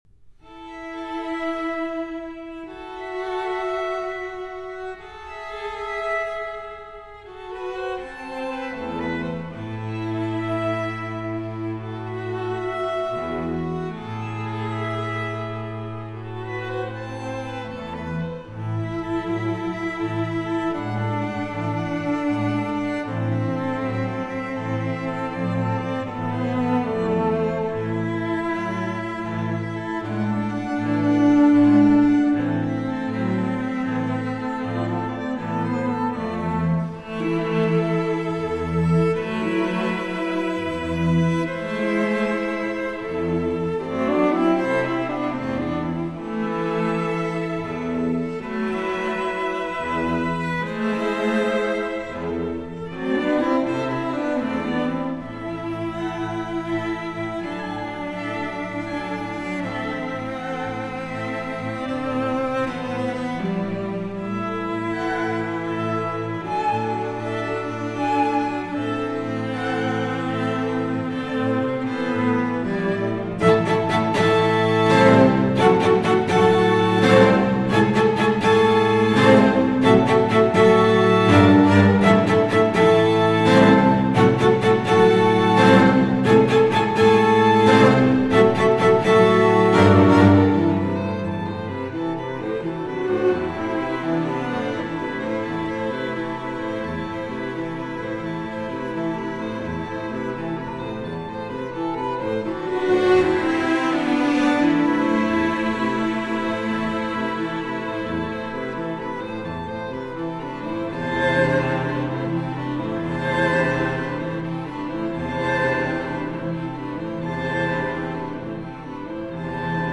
虽然没有和声变化和旋律变奏，所有乐器发出的似乎是同一个声音，音乐却并未显得单调乏味，相反 色彩丰富，形态饱满，亲切和谐。